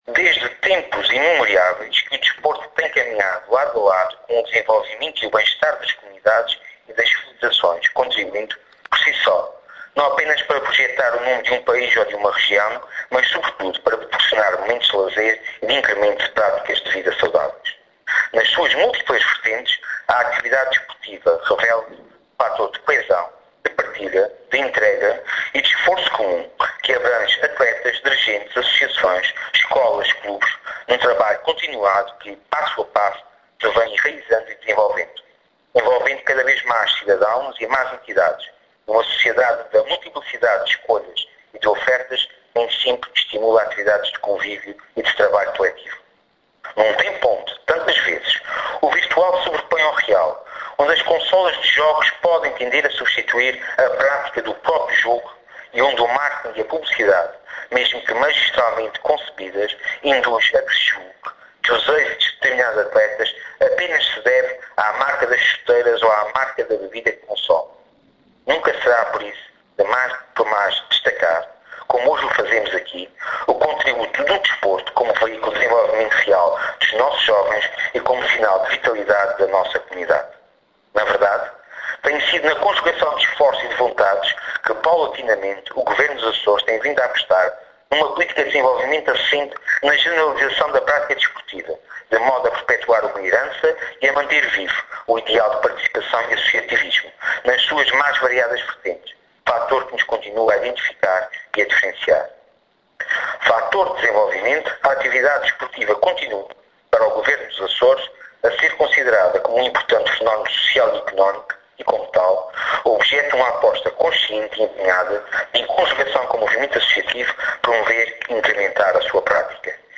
Ouvir a interven��o completa do Vice-Presidente do Governo Regional S�rgio �vila